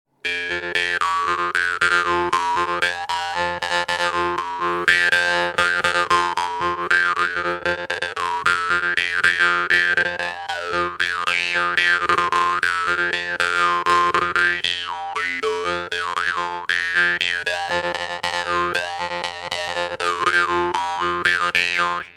Его вибрирующие, гипнотические тона идеальны для медитации, релаксации и погружения в этническую атмосферу.
Игра на варгане